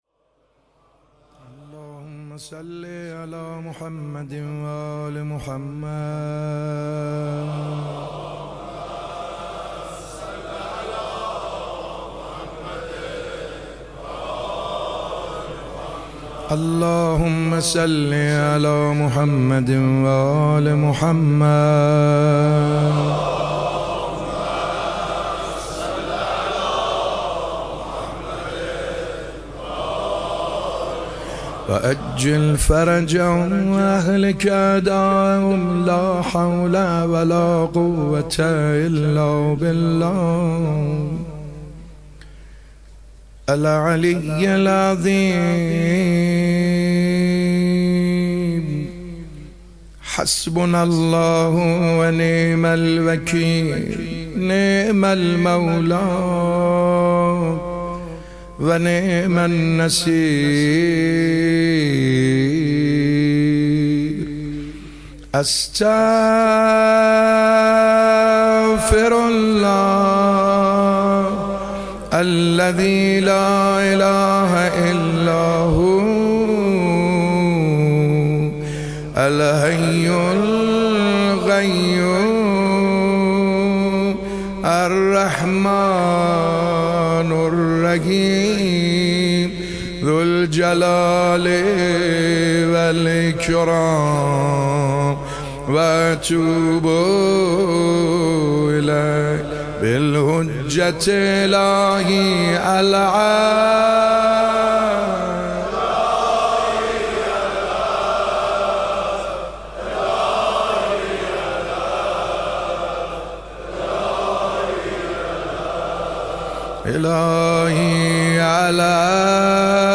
شب چهارم محرم 97 - روضه - باید تمام منتظران را خبر کنیم